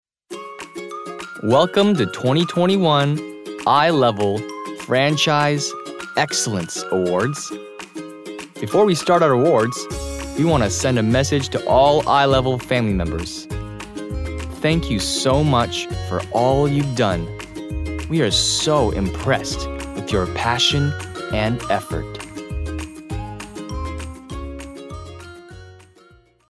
Voice actor sample
차분/편안